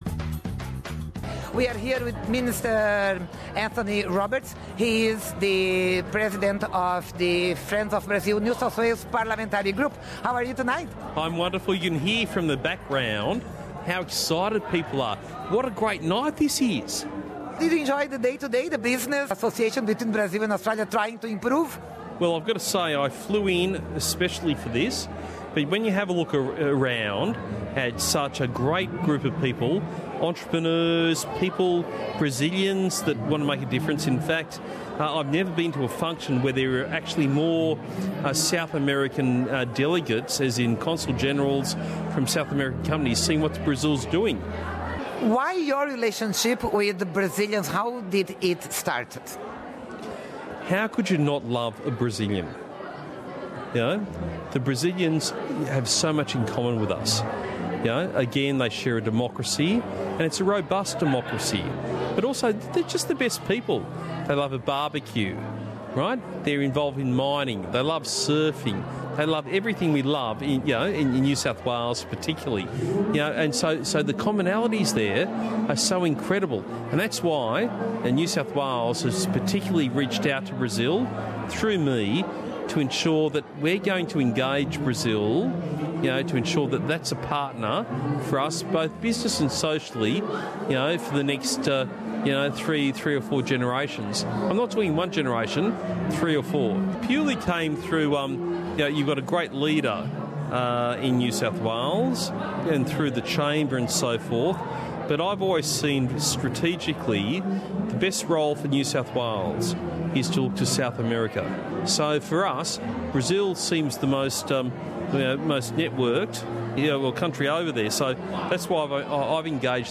Anthony Roberts, líder do do Grupo "Amigos do Brasil"do parlamento de Nova Gales do Sul, diz que o estado quer estreitar as relações comerciais com o Brasil, para que seja tão bem-sucedidas como o fluxo sempre crescente de estudantes brasileiros para a Austrália. Acompanhando a Missão Empresarial Brasileira à Austrália no evento "Brazil at the Harbour", o ministro da Indústria, Recursos e Energia de Nova Gales do Sul e líder do governo no Parlamento deu entrevista à Rádio SBS na última quinta-feira, 8 de setembro.